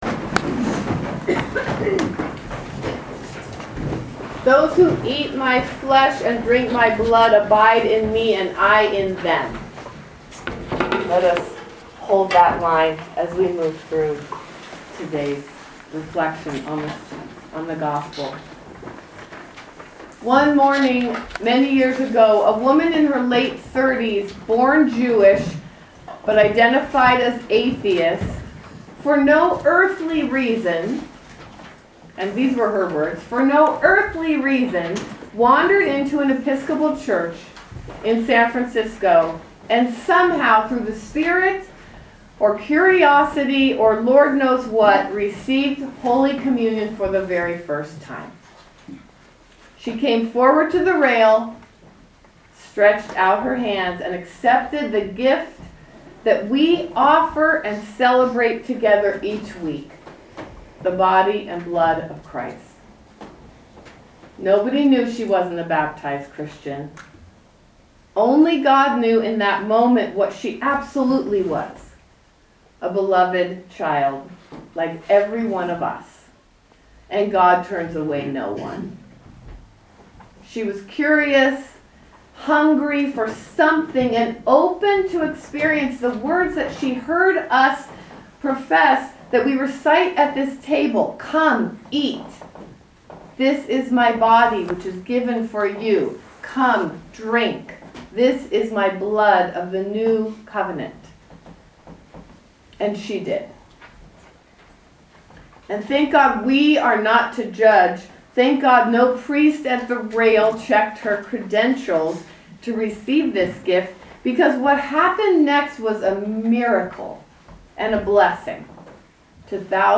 Sermons
St. John's Episcopal Church Chapel of the Transfiguration